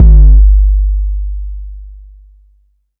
BOOMIN 808 3 SHORT.wav